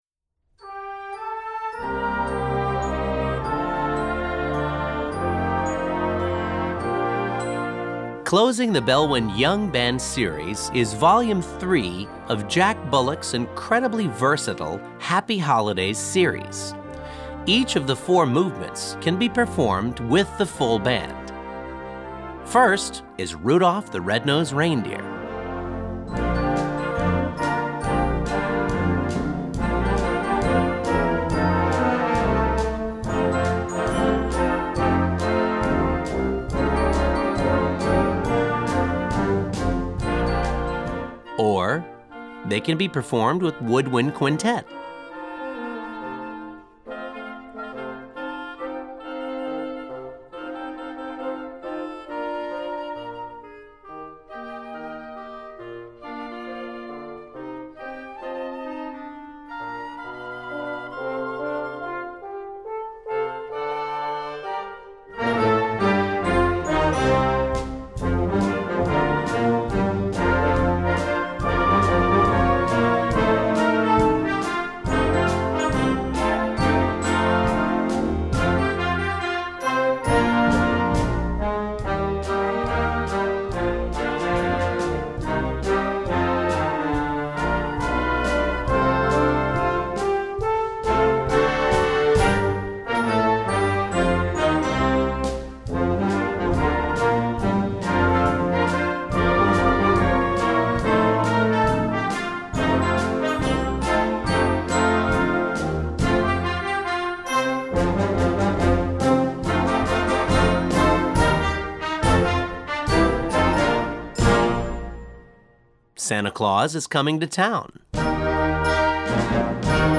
Gattung: Weihnachtsmedley
Besetzung: Blasorchester